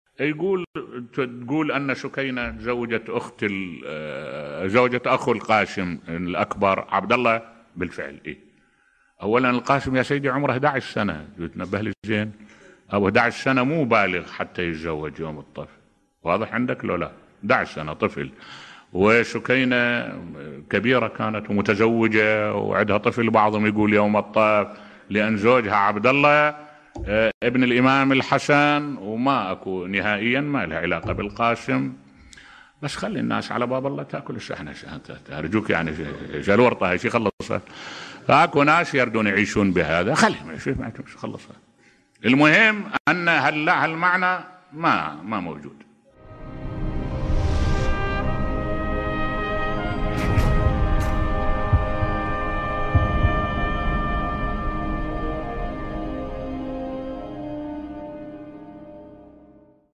ملف صوتی حقيقة زواج القاسم إبن الإمام الحسن (ع) من سكينة بصوت الشيخ الدكتور أحمد الوائلي